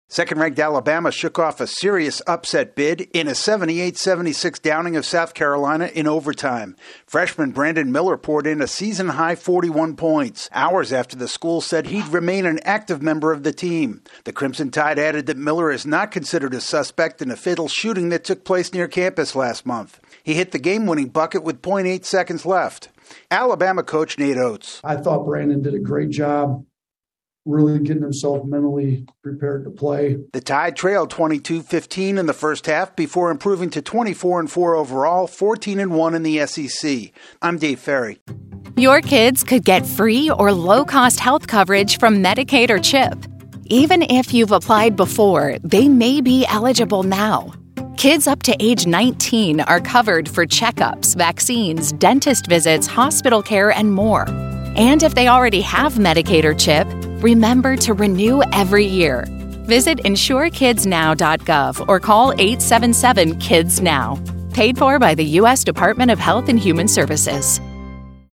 Alabama caps a tumultuous two days by outlasting South Carolina in overtime. AP correspondent